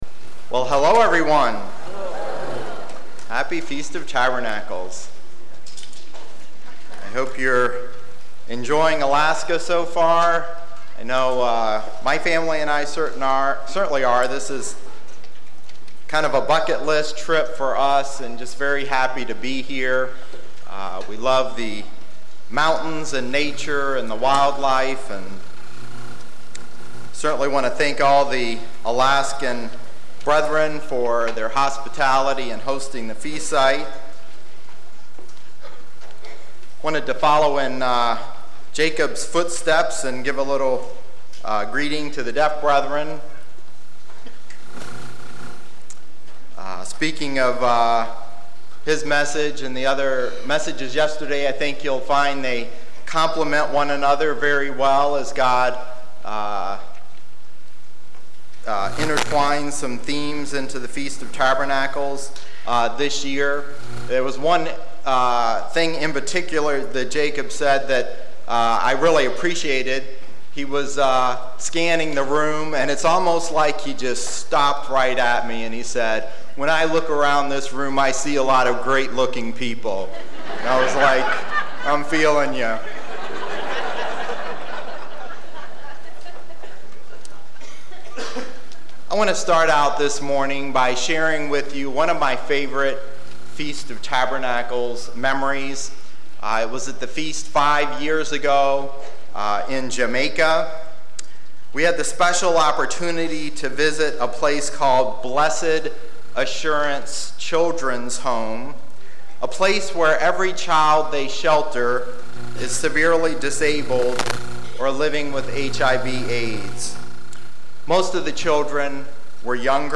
This sermon was given at the Anchorage, Alaska 2018 Feast site.